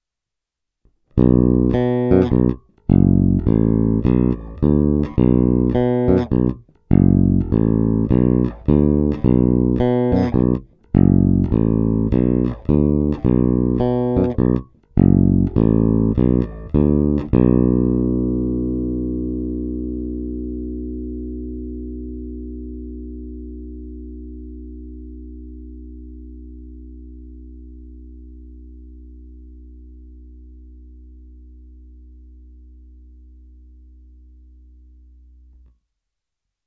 Ukázky jsou nahrány rovnou do zvukové karty a jen normalizovány.
Snímač u kobylky